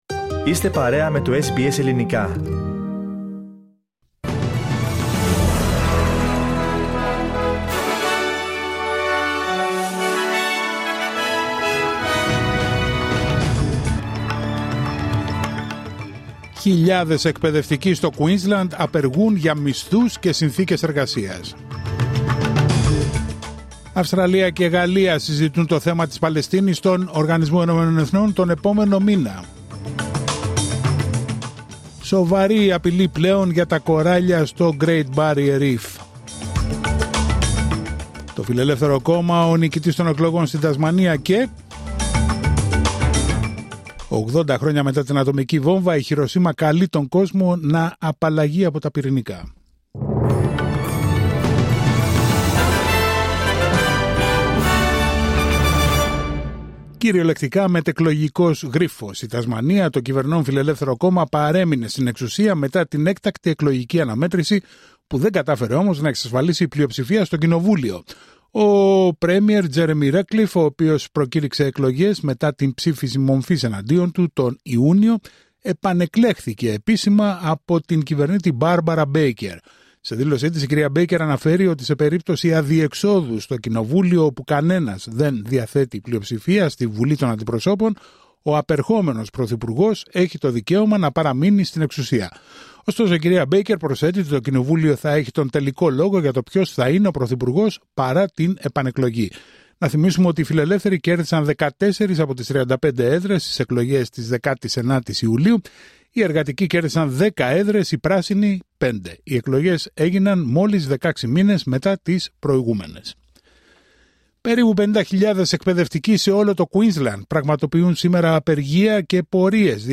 Δελτίο ειδήσεων Τετάρτη 6 Αυγούστου 2025